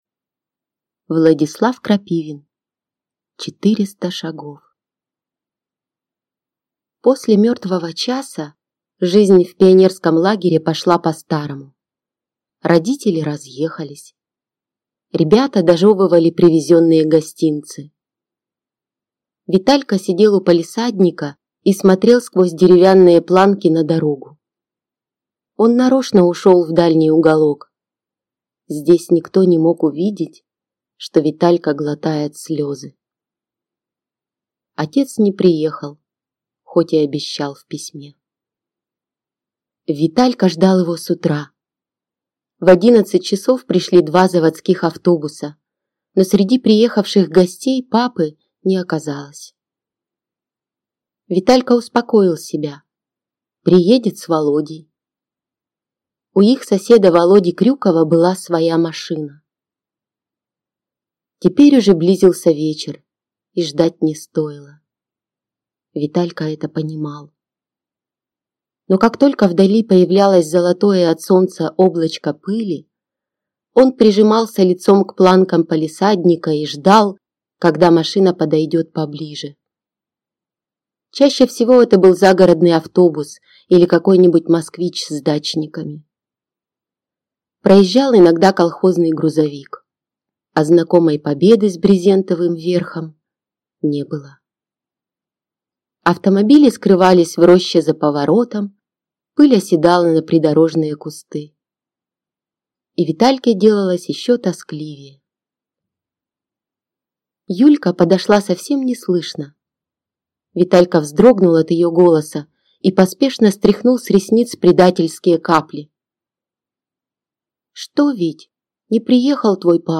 Аудиокнига Четыреста шагов | Библиотека аудиокниг